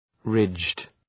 Shkrimi fonetik {rıdʒd}